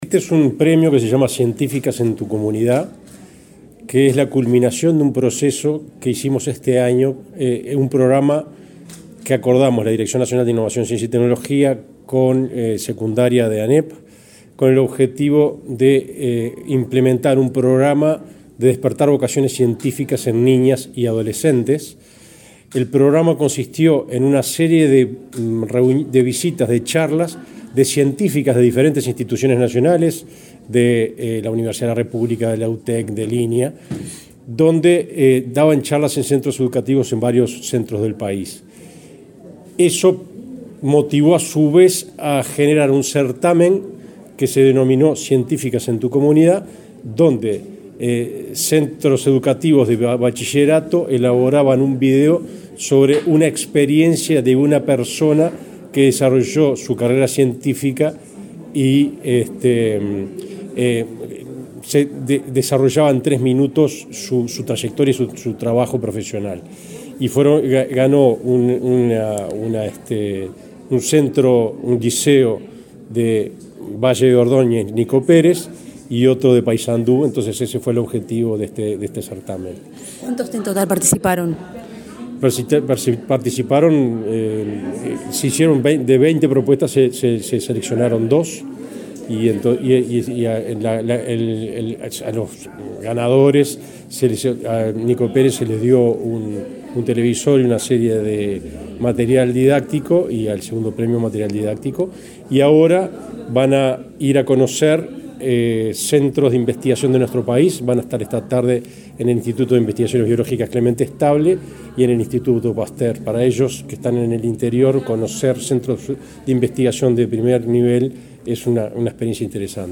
Entrevista al director nacional de Innovación, Ciencia y Tecnología del MEC
El director nacional de Innovación, Ciencia y Tecnología del Ministerio de Educación y Cultura, Alberto Majó, dialogó con Comunicación Presidencial,